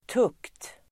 Uttal: [tuk:t]